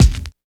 81 SCRT KICK.wav